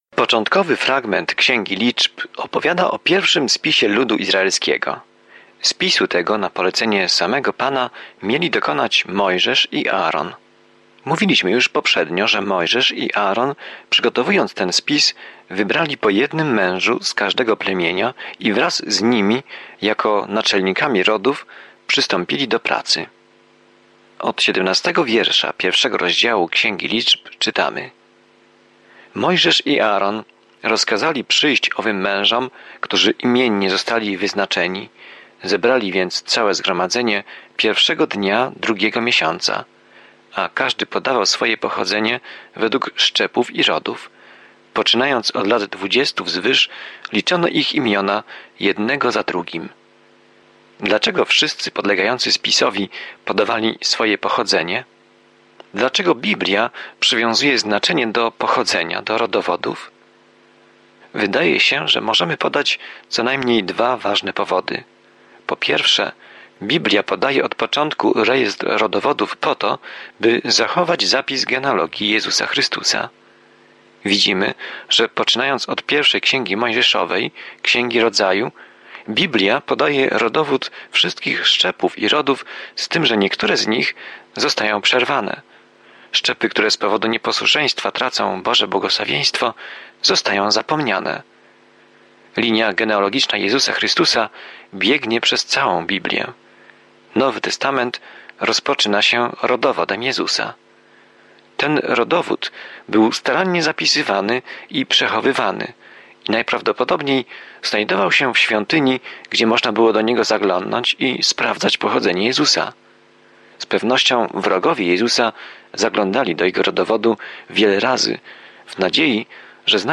Pismo Święte Liczb 1:17-54 Liczb 2 Dzień 1 Rozpocznij ten plan Dzień 3 O tym planie W Księdze Liczb spacerujemy, wędrujemy i oddajemy cześć Izraelowi przez 40 lat na pustyni. Codziennie podróżuj po Liczbach, słuchając studium audio i czytając wybrane wersety słowa Bożego.